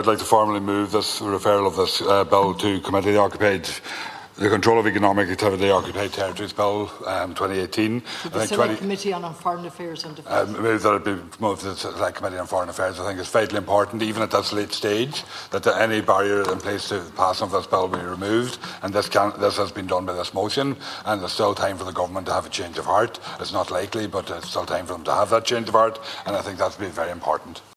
Well, Donegal Deputy Thomas Pringle says Government still has time to do what he believes is the right thing and pass the bill without further delay: